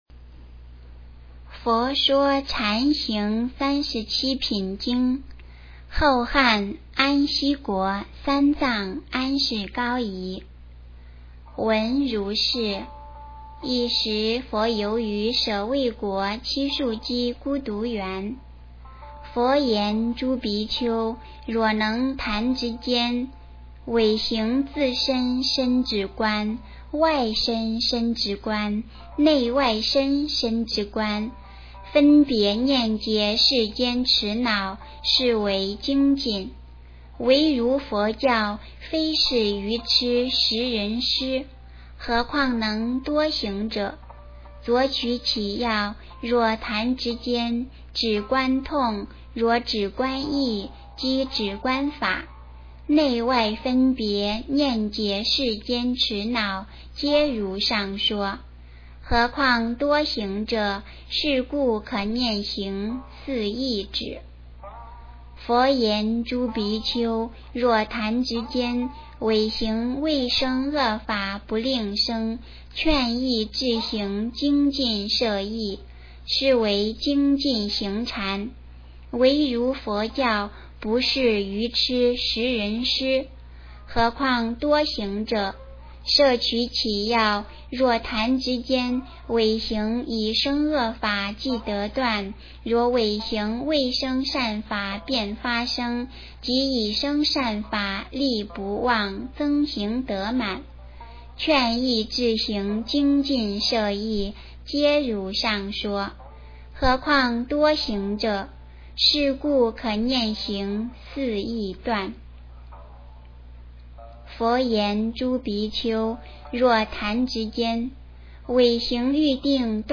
诵经
佛音 诵经 佛教音乐 返回列表 上一篇： 金刚经-大乘正宗分第三 下一篇： 圆觉经 相关文章 光明指引--未知 光明指引--未知...